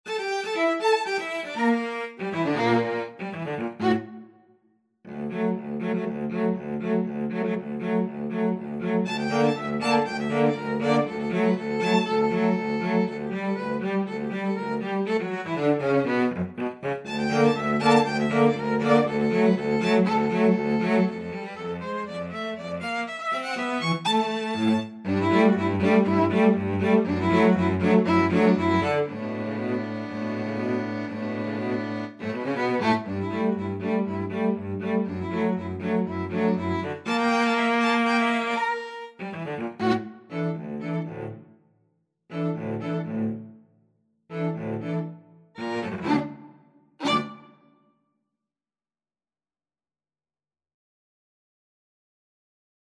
Allegro giusto (View more music marked Allegro)
2/4 (View more 2/4 Music)
Classical (View more Classical Violin-Cello Duet Music)